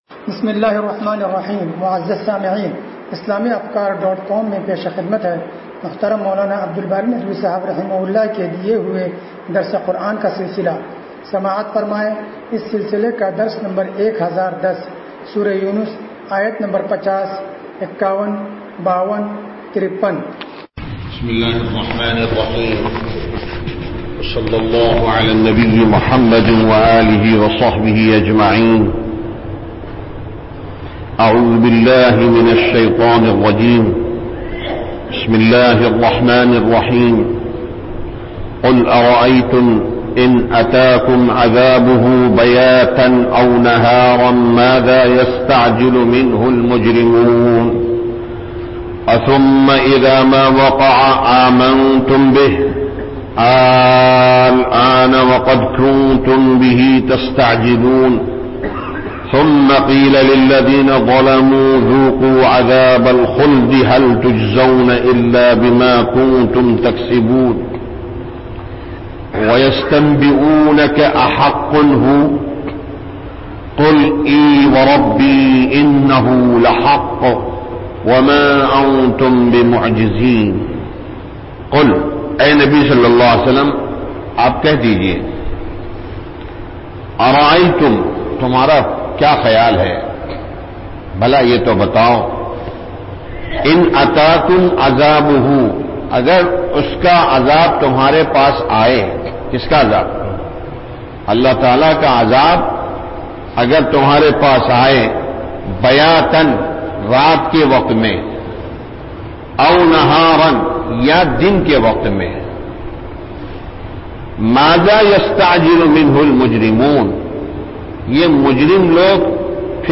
درس قرآن نمبر 1010